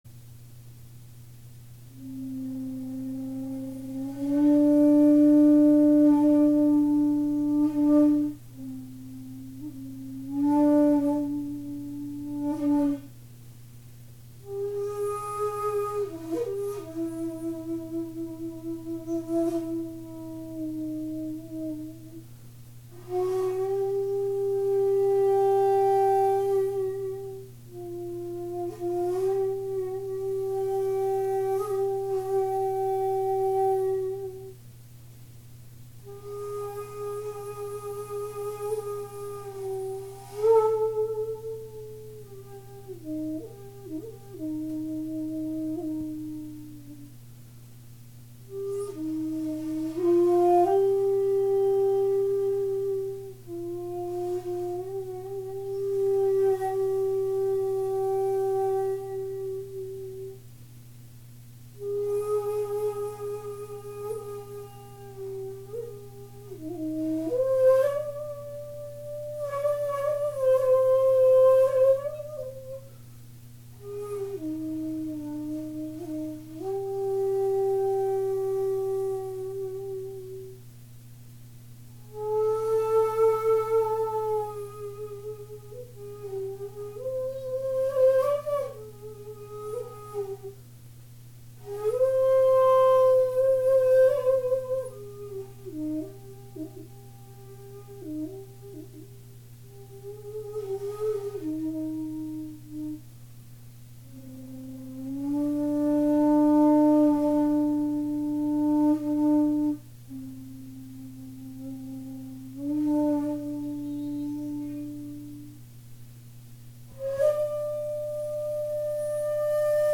そういう時代背景もあってか、この曲は深い悲しみに満ちた本曲です。